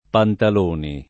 [ pantal 1 ni ]